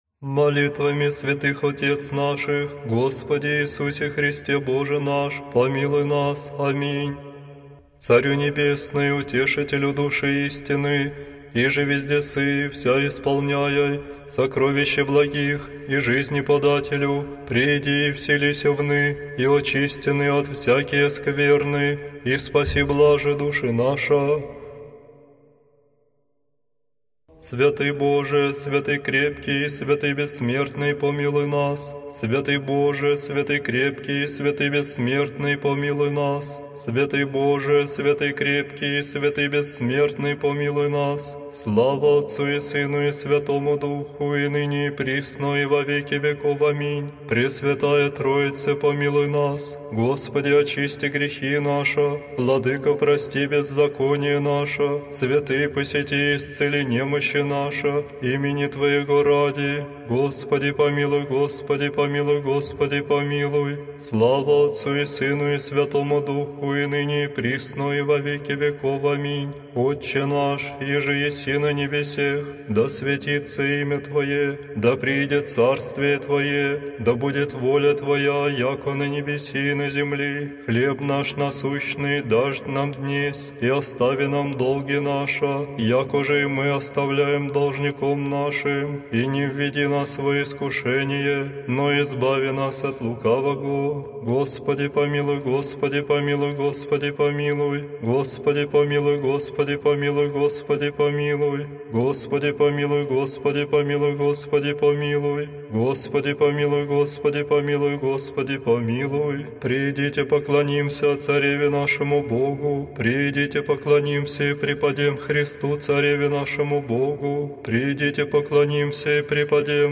Аудиокнига Молитвы утренние и вечерние. Правило ко святому причащению | Библиотека аудиокниг